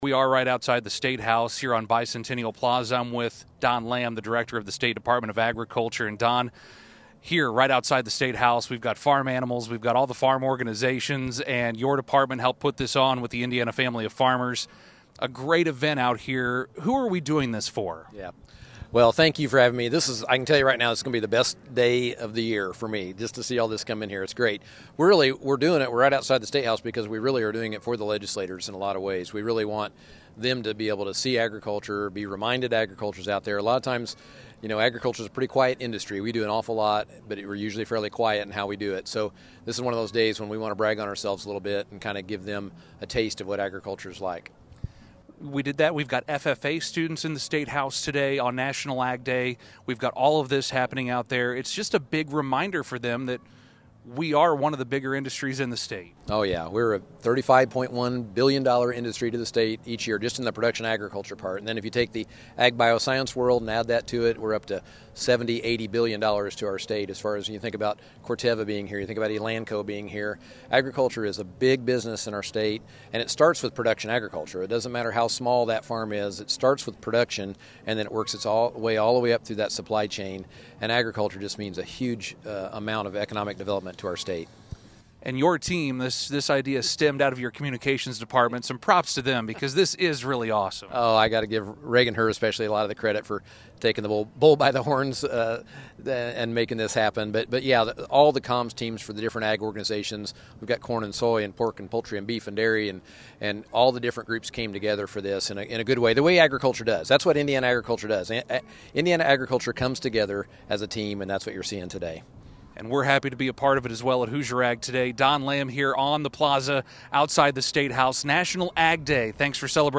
Hear our interview about the event with Don Lamb, director of the Indiana State Dept. of Agriculture, below.